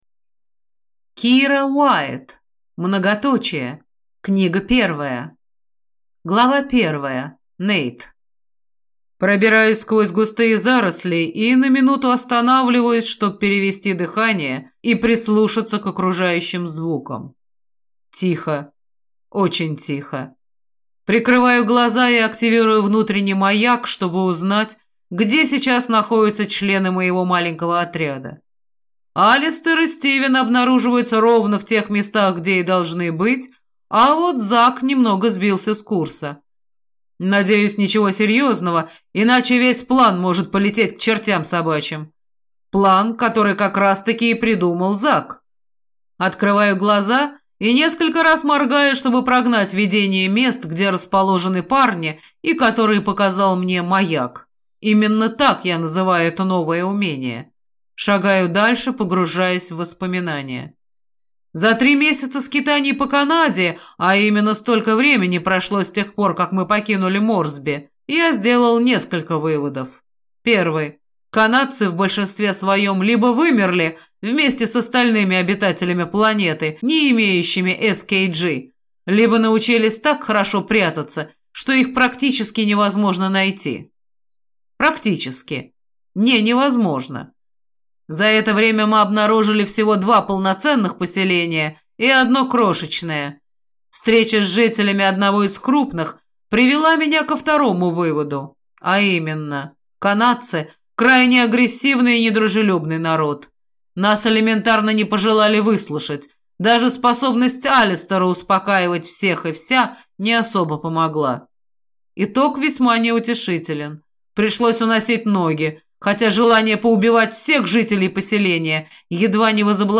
Аудиокнига Многоточия | Библиотека аудиокниг